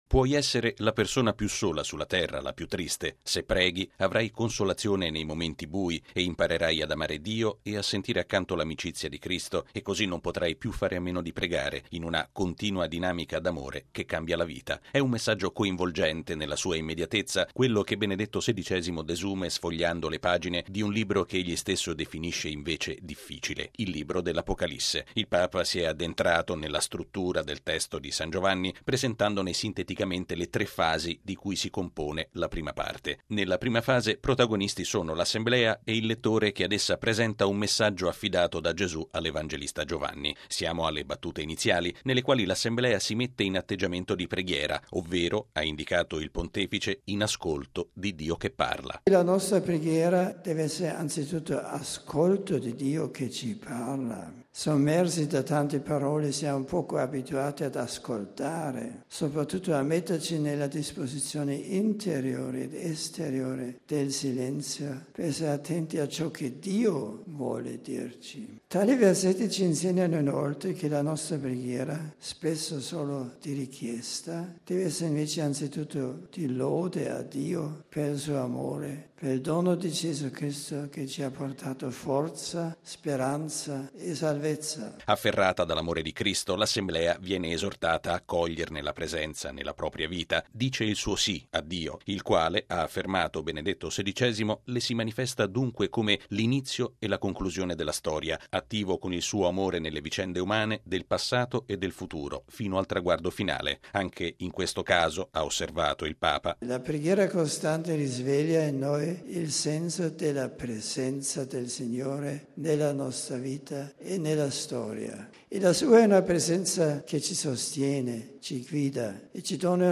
Il Papa all'udienza generale: Gesù tiene nelle sue mani la Chiesa di tutti i tempi
◊   È Cristo che ha in mano i destini della Chiesa e per capirne gli orientamenti un cristiano non ha che un modo: pregare. All’udienza generale nuovamente celebrata in Aula Paolo VI, dopo la parentesi estiva a Castel Gandolfo, Benedetto XVI ha proseguito la sua “scuola di preghiera”, incentrando la catechesi sul Libro dell’Apocalisse.